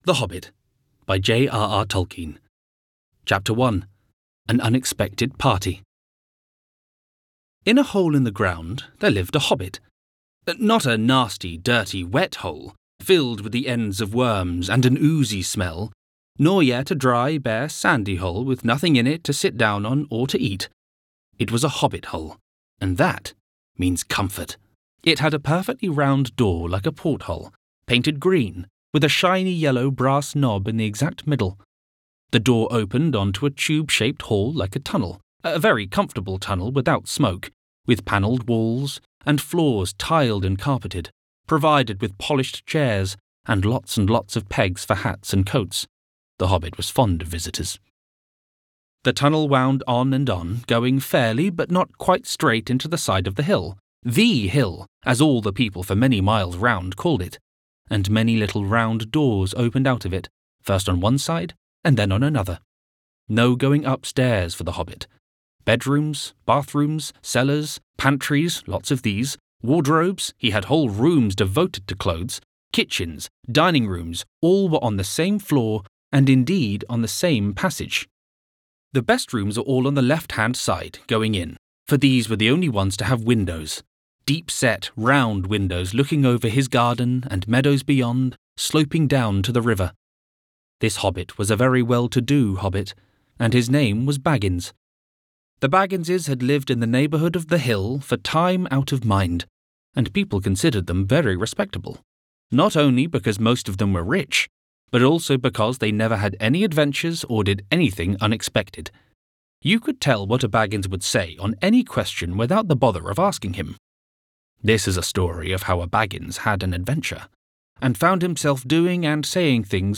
Audiobook Showreel
Male
Neutral British
British RP
Cool
Youthful
Confident
Friendly